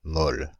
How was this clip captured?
Paris France (Île-de-France)